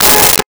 Car Horn 01
Car Horn 01.wav